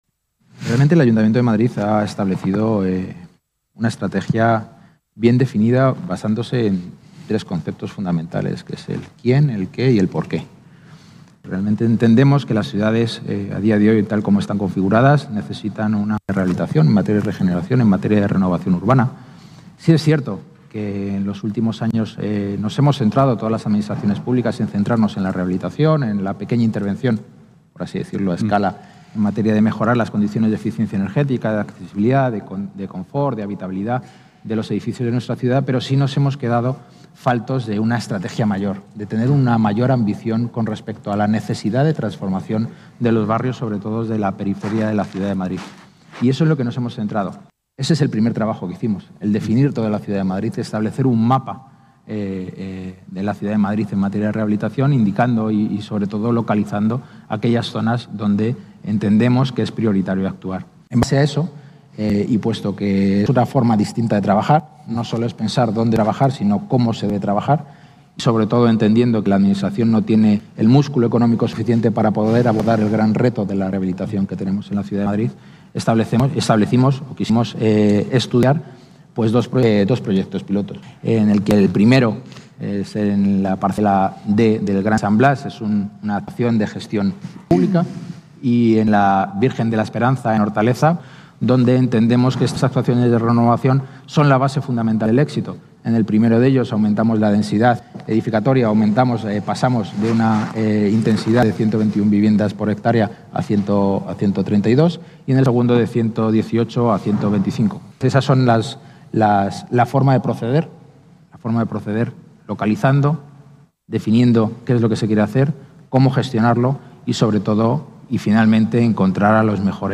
Ha participado en el coloquio ‘Origen y estructura de proyectos de regeneración urbana, arquitectónica y energética: dos experiencias piloto en Madrid’